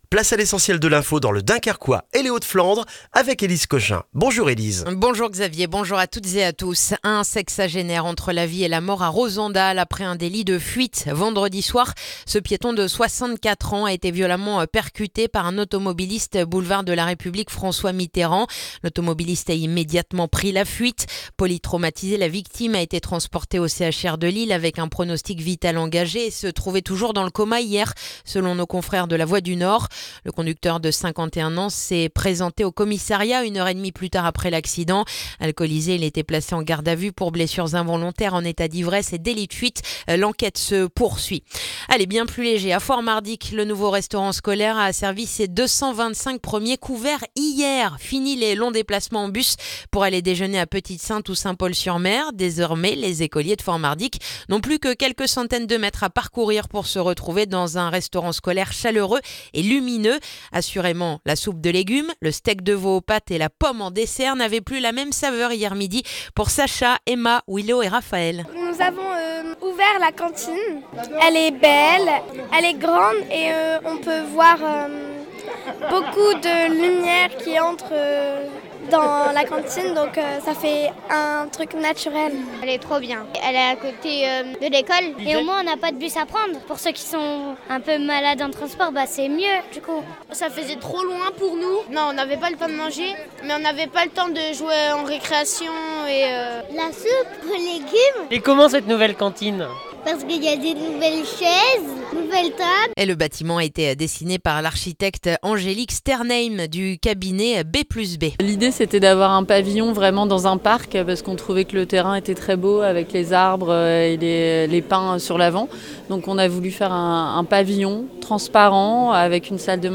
Le journal du mardi 3 mars dans le dunkerquois